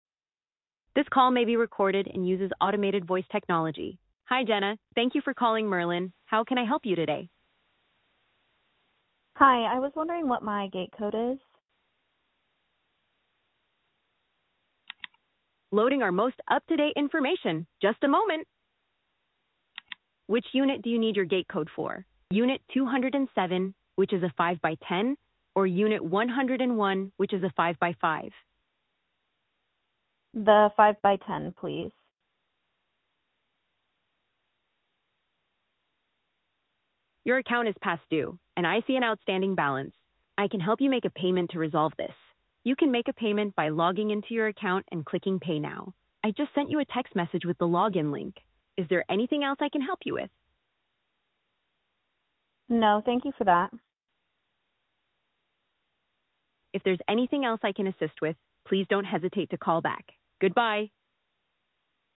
past due voice call to see how our Voice agent navigates multiple leases in different scenarios.